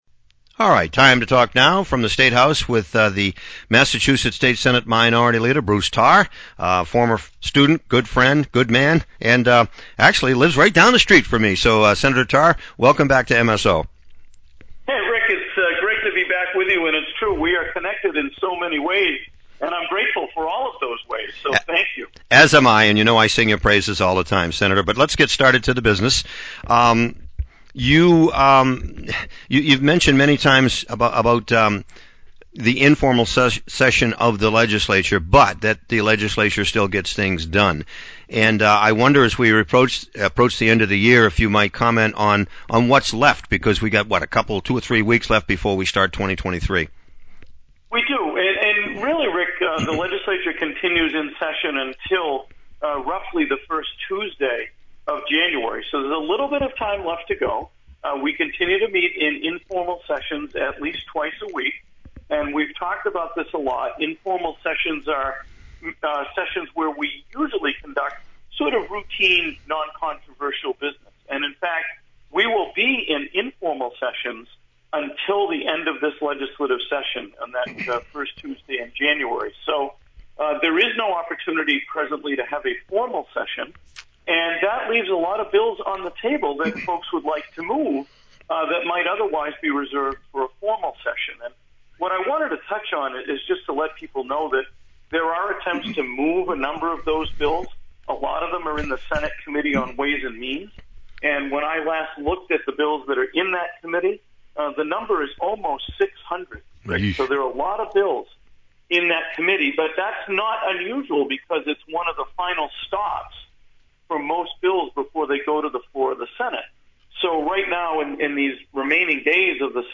(Audio) From the State House with MA Senate Minority Leader Bruce Tarr – Many Bills Still to Be Passed – Praise for Outgoing/Incoming Administrations – Toy Drive is Underway.